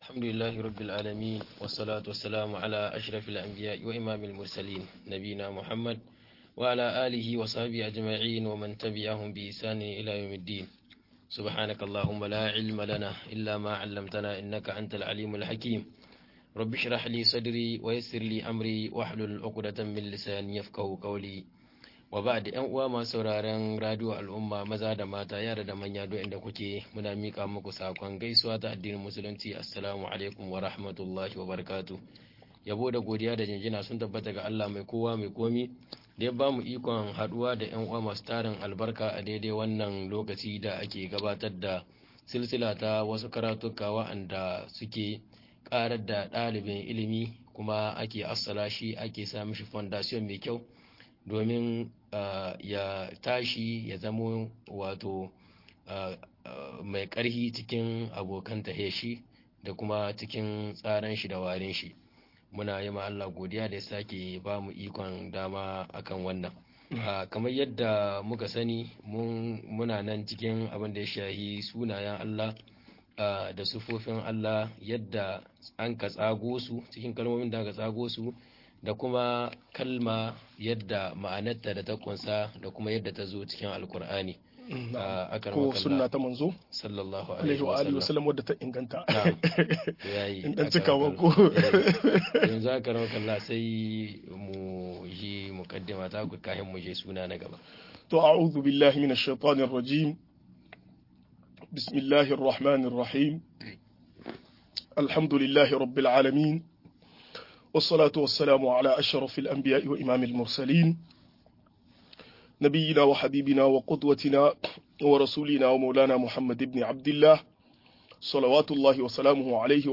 Sunayen Allah da siffofin sa-15 - MUHADARA